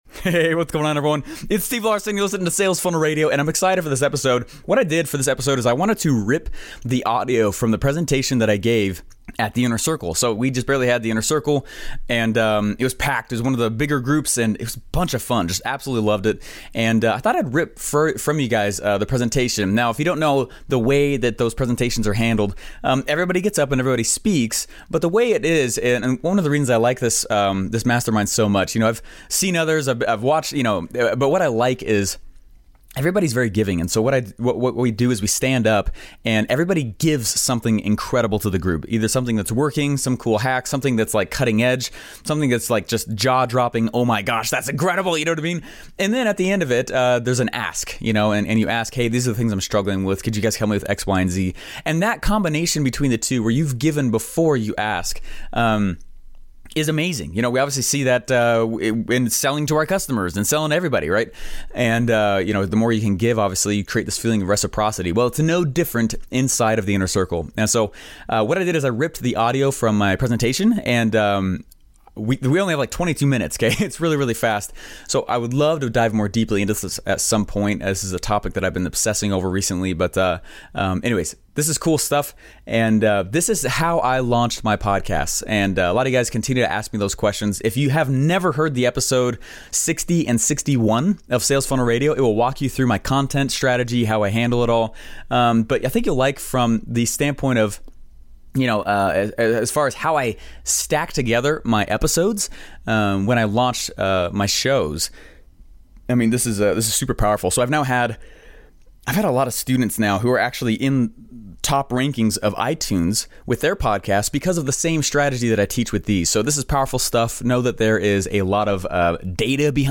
If you wanna know how I've launched all my content, I ripped the audio from my presentation at Russell Brunson's Inner Circle...